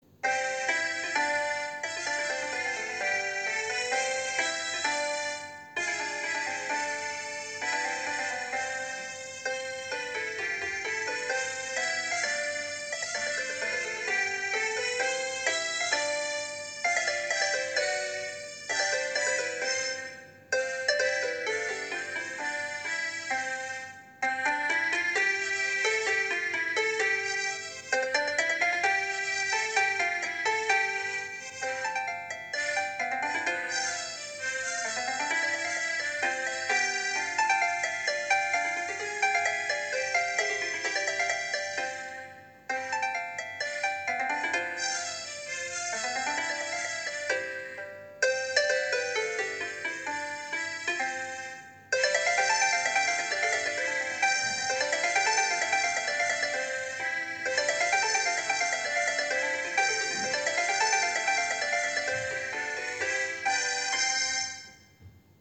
ویولون تار قانون
تار،سه تار
سنتور
قانون
در ماهور دو